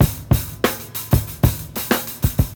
126 Bpm Breakbeat Sample C# Key.wav
Free drum groove - kick tuned to the C# note. Loudest frequency: 3252Hz
126-bpm-breakbeat-sample-c-sharp-key-Loj.ogg